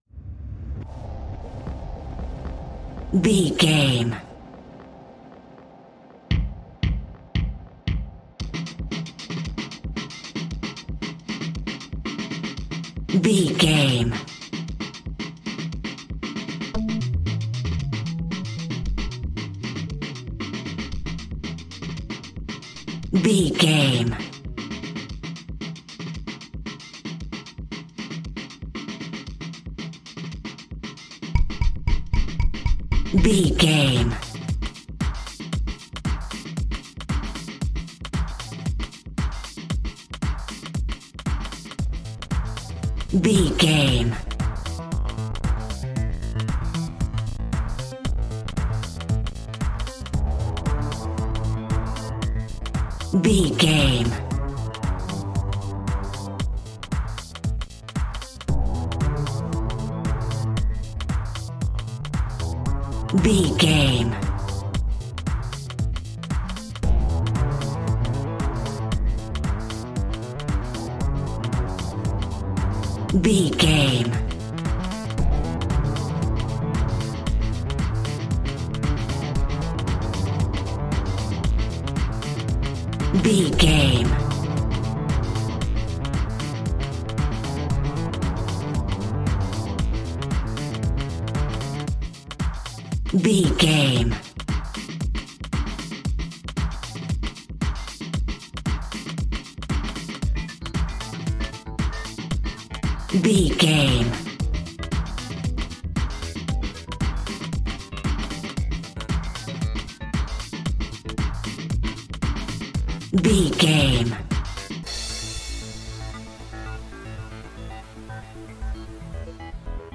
Ionian/Major
groovy
futuristic
hypnotic
synthesiser
drum machine
electric guitar
Drum and bass
break beat
electronic
sub bass
synth leads